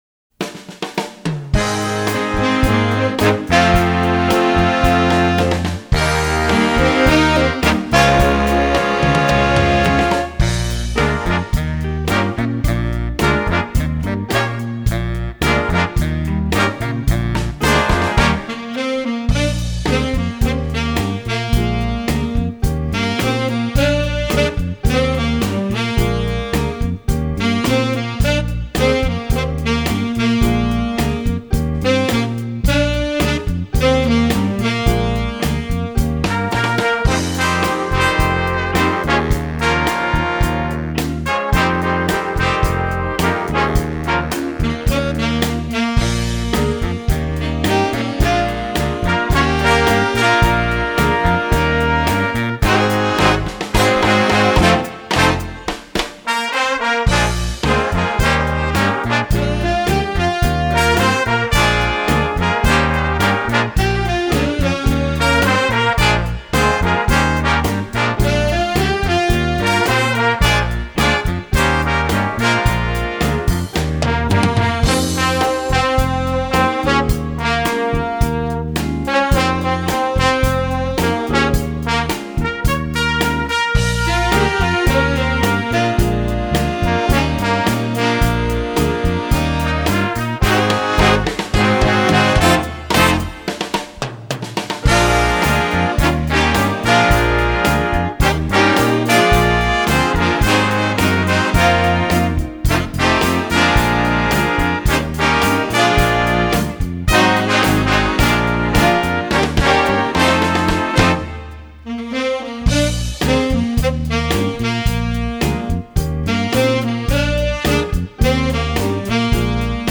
Répertoire pour Jazz band - Jazz Band